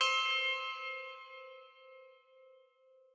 bell1_9.ogg